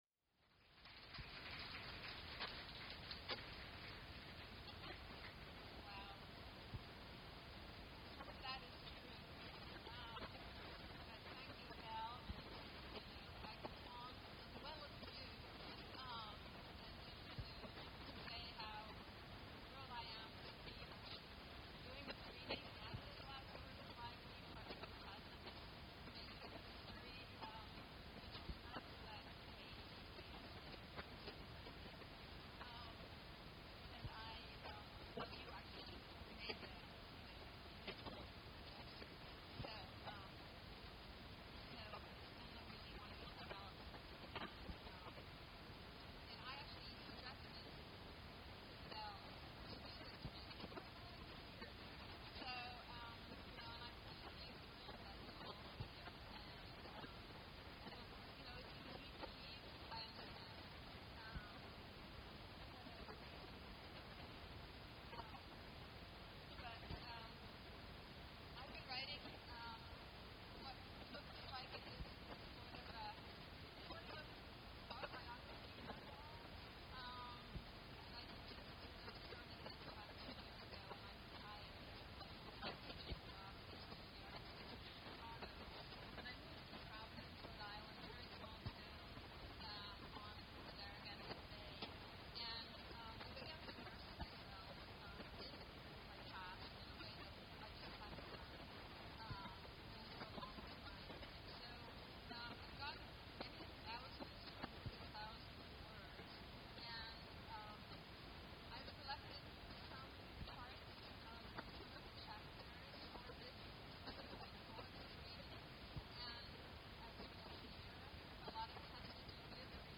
Faith Wilding reads from her unpublished memoir at Glasgow Women’s Library, June 5, 2013.
Artist, Faith Wilding presents a reading from her autobiography-in-process.